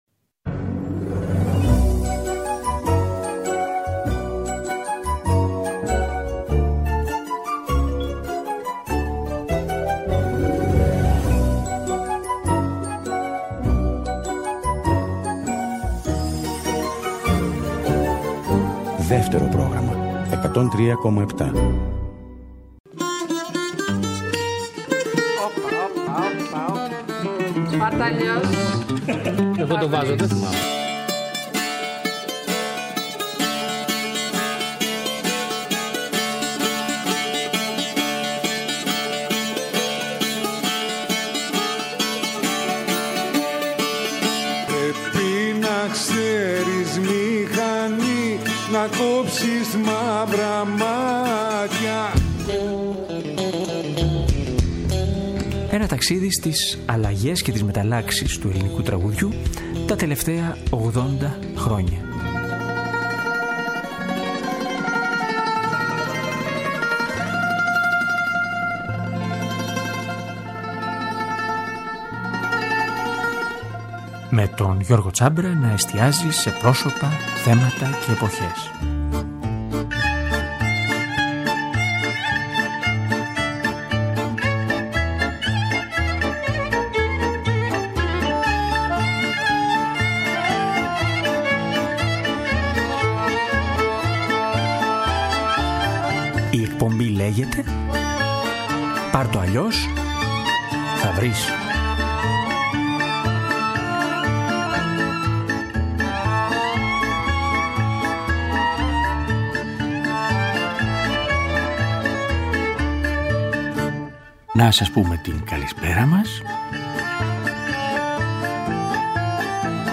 Θέματα, «σημειώσεις» και κυρίως τραγούδια από όσα πέρασαν από την εκπομπή σ’ αυτό το διάστημα.